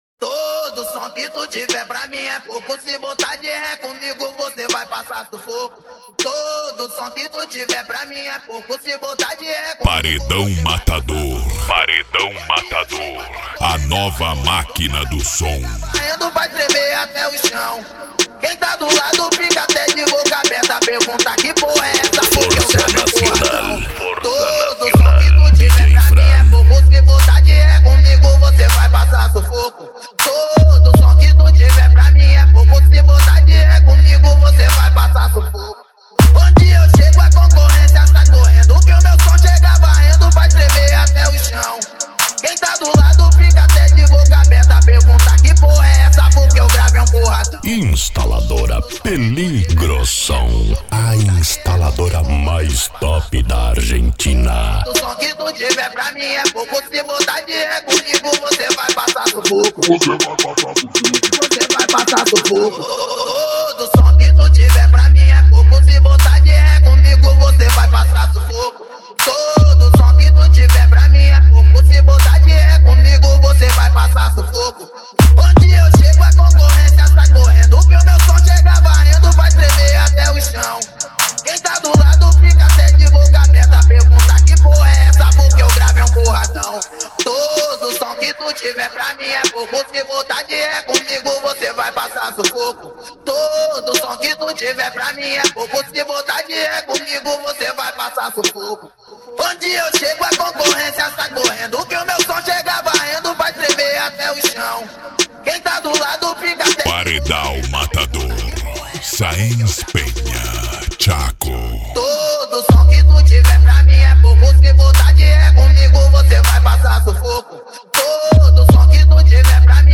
Bass
Mega Funk
Psy Trance